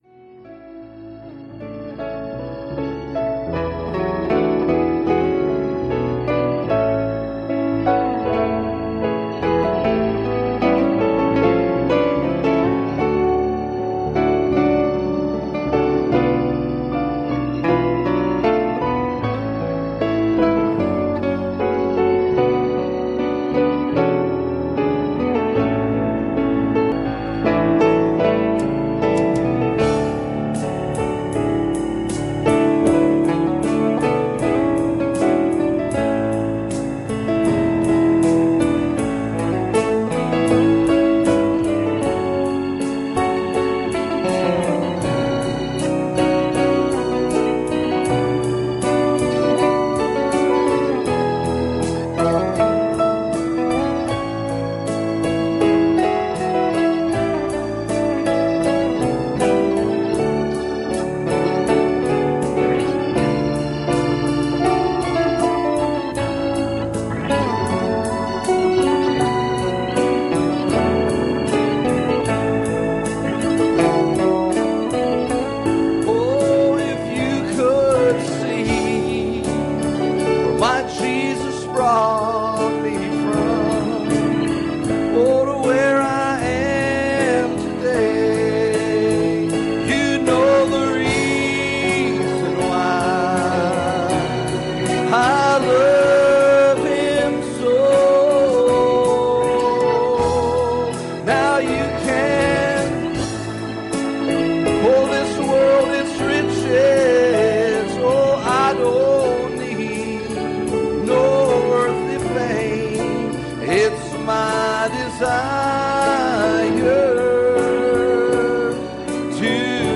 Numbers 22:12 Service Type: Sunday Morning "He told Balaam